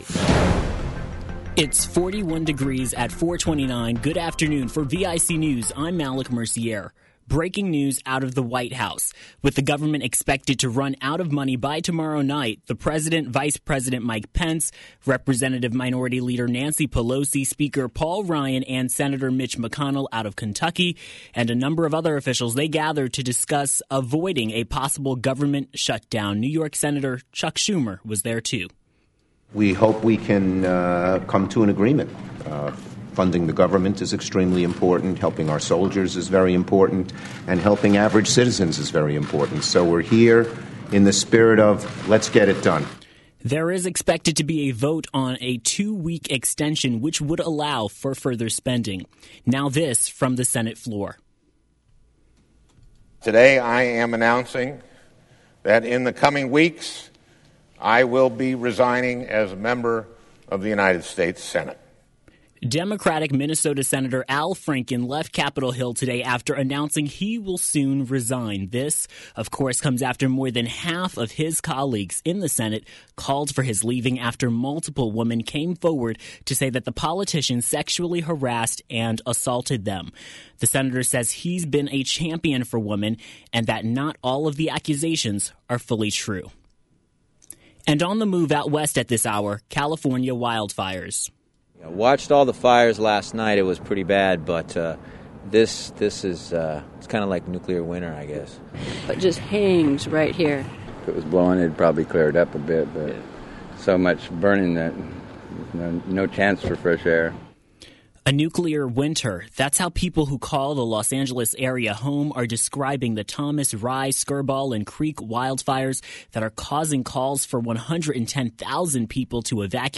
Radio News 🎧